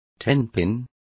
Complete with pronunciation of the translation of tenpins.